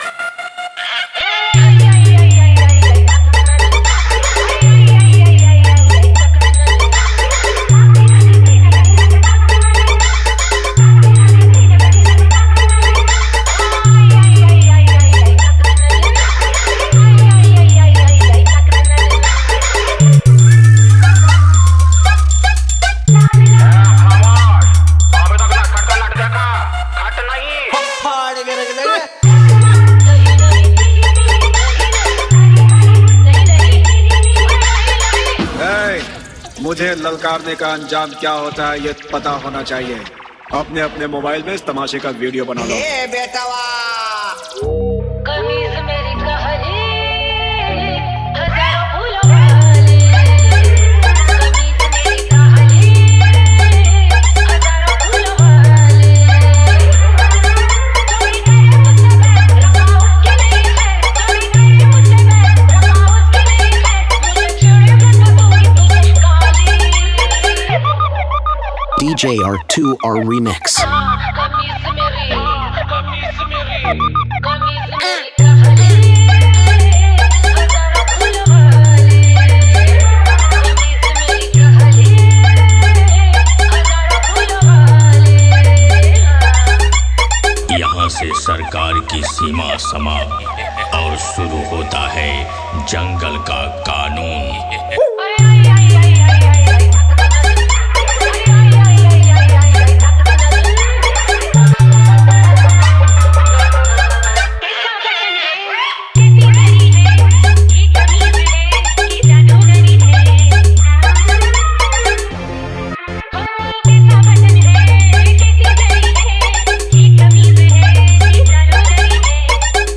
New Style 1 Step Long Humming Dance Bass Mix 2024